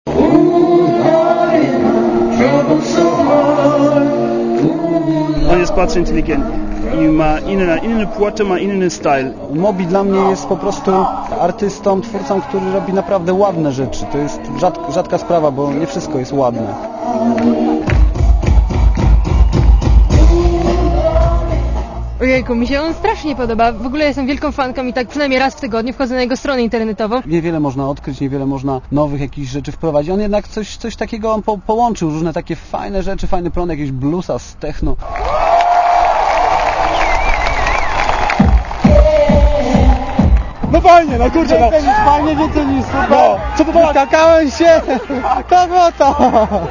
Komentarz audio (184Kb)